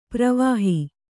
♪ pravāhi